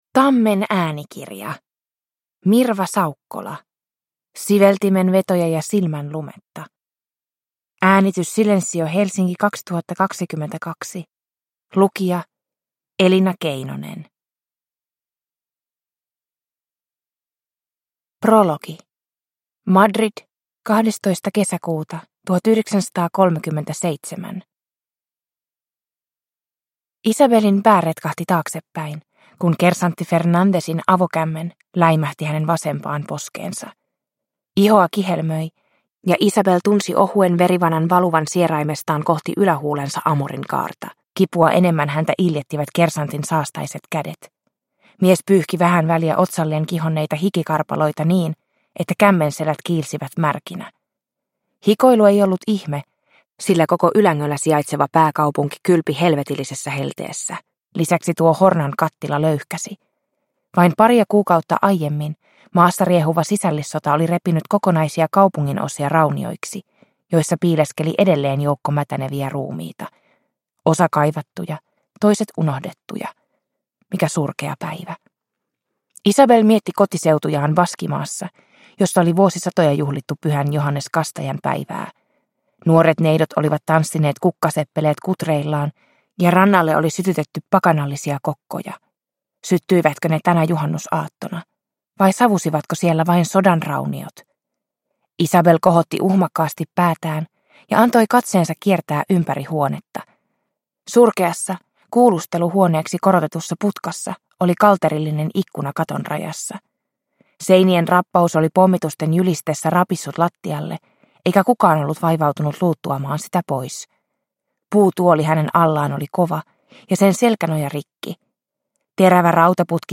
Siveltimenvetoja ja silmänlumetta – Ljudbok – Laddas ner